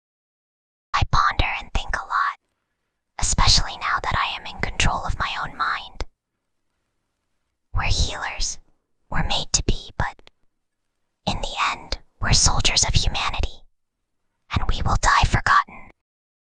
Whispering_Girl_18.mp3